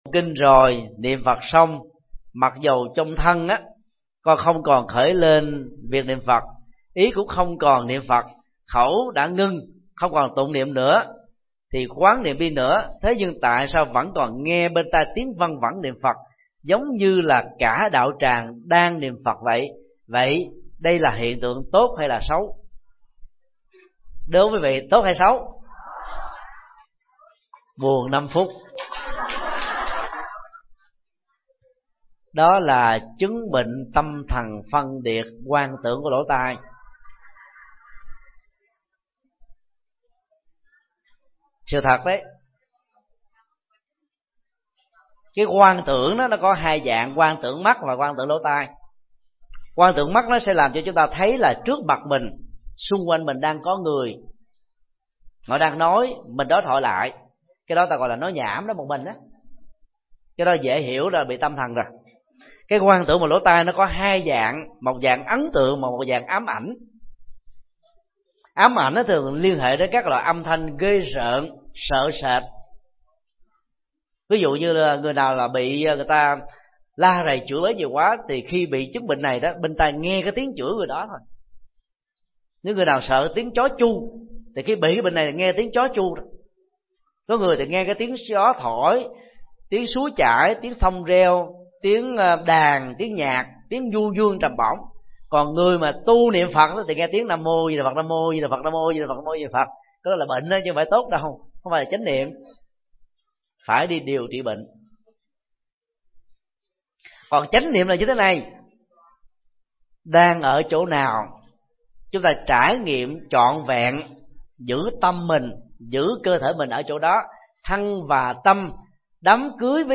Vấn đáp: Vọng niệm khi đọc-trì-tụng Kinh – Thích Nhật Từ